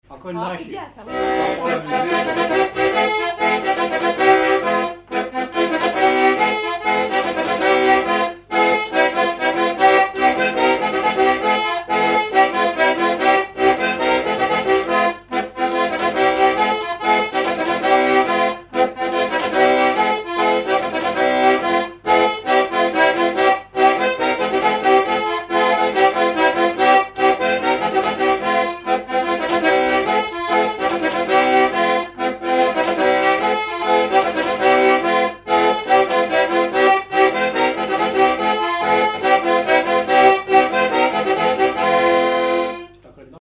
Localisation Noirmoutier-en-l'Île (Plus d'informations sur Wikipedia)
Thème : 0074 - Divertissements d'adultes - Couplets à danser
Fonction d'après l'analyste danse : branle : courante, maraîchine ; danse : branle ;
Catégorie Pièce musicale inédite